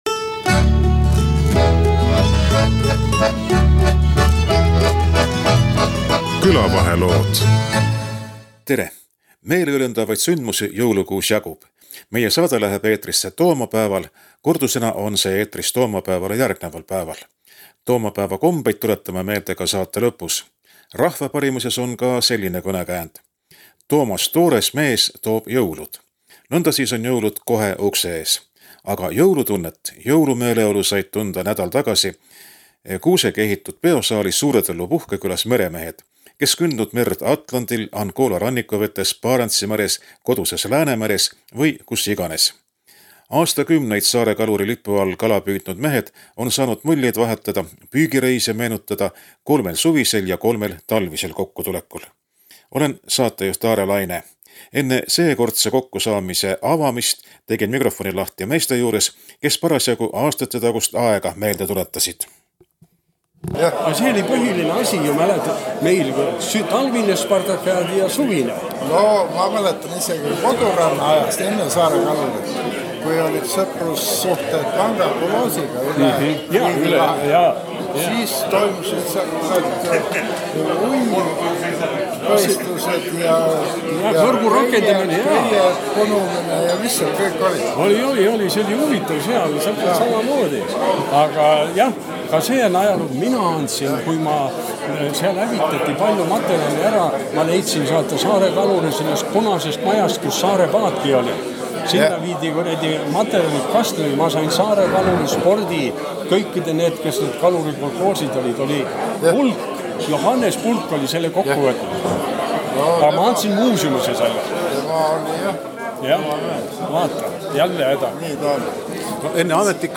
Seejärel saavad sõna nädal tagasi Suure Tõllu puhkekülas kokku saanud meremehed, kes kündnud vetevoogusid Atlandil, Angola rannikumeres, Barentsi meres, koduses Läänemeres või kus iganes. Mehed meenutavad kaugeid püügireise ja räägivad ka sellest, millega praegu tegelevad.